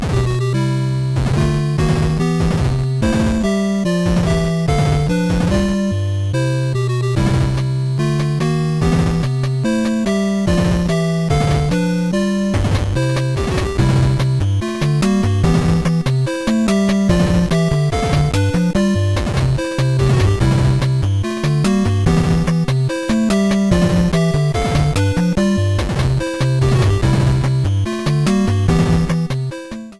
Started partway through the track and fadeout